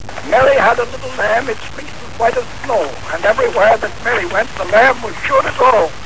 Hear Edison recite "Mary had a little lamb."